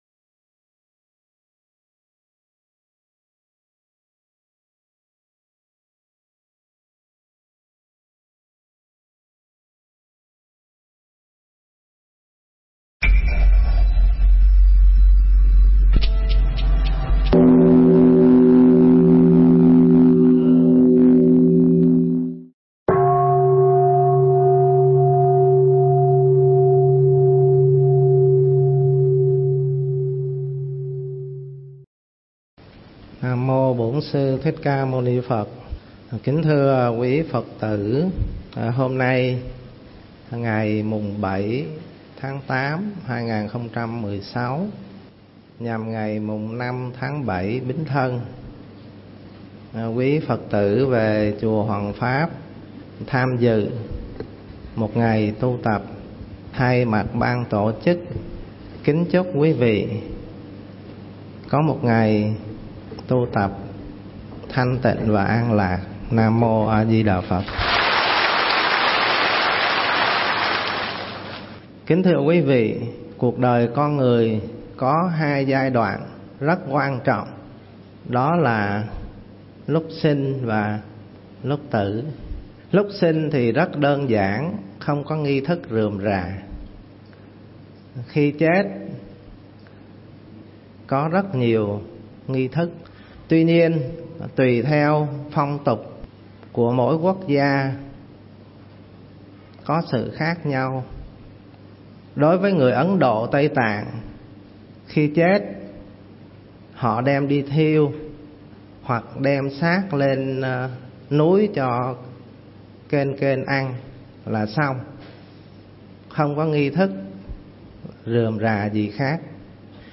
Nghe Mp3 thuyết pháp Tống Táng Giản Đơn